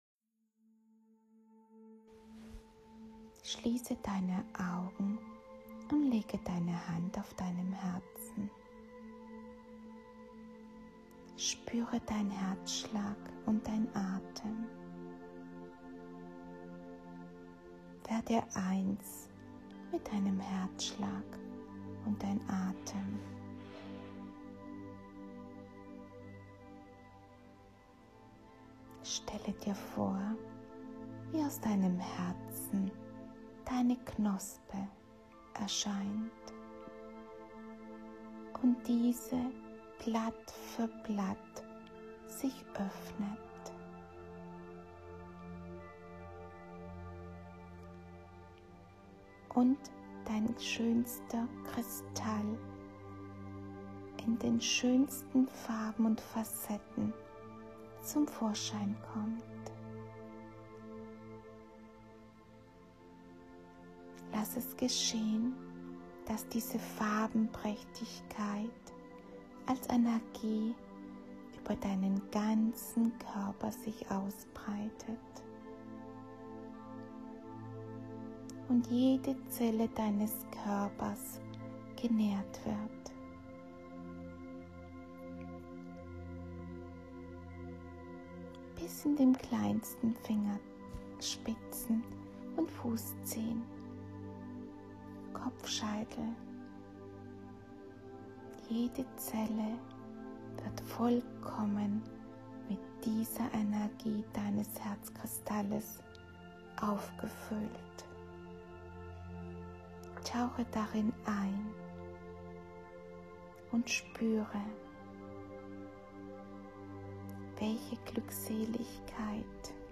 Durch Meditationen im Alltag mehr innere Ruhe und Ausgeglichenheit erreichen. Höre dir meine geführten Meditationen an und komme ganz bei dir an.
meditation-garden-eden-.mp3